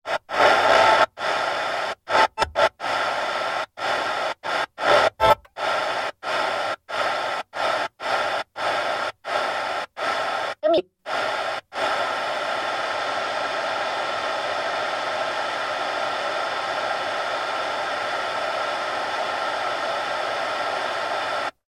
Car radio static and tuning into stations
Tags: Sound Fx